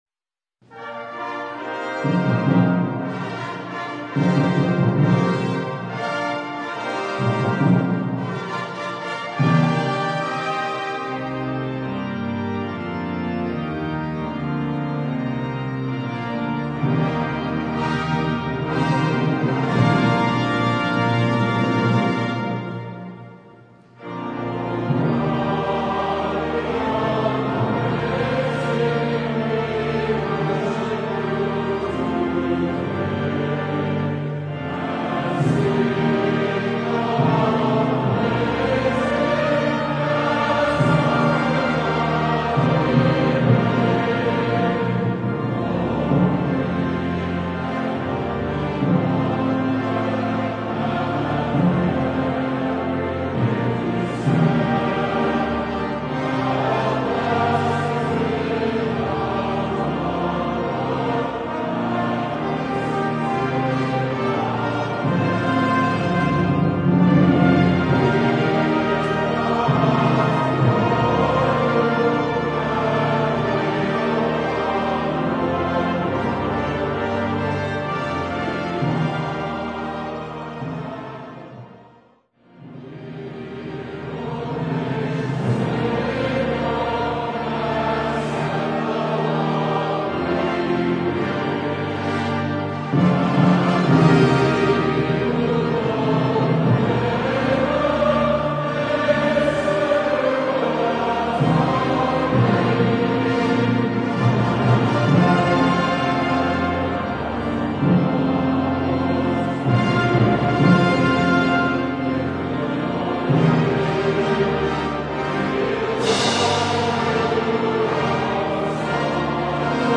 Voicing: Congregation